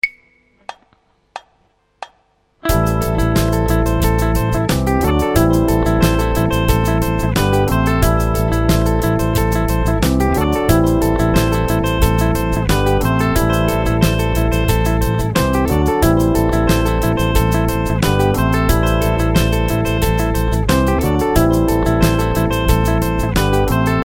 The final example is another arpeggio idea but this time using faster 16th notes. The A minor chord is played using the D minor chord shape (again from the CAGED Chord System). The 4 notes at the end of each bar are from the C D G and A minor chords.